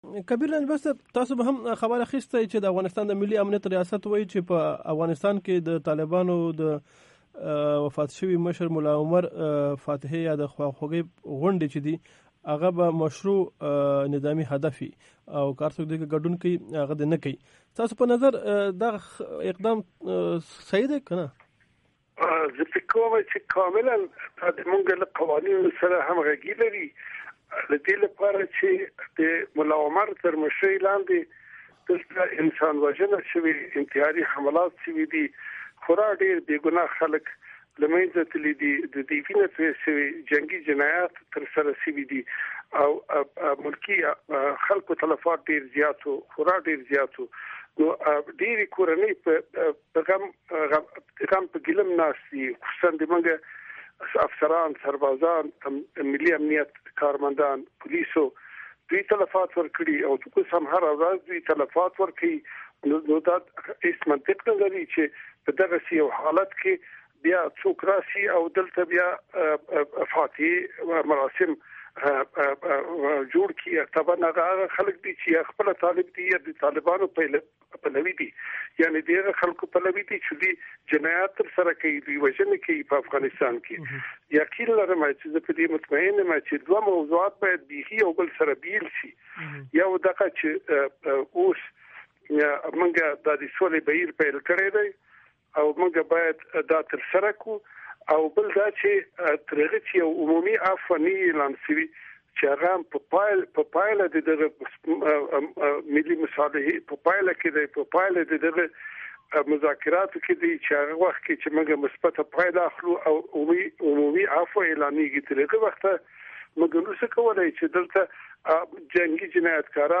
Kabeer Ranjbar interview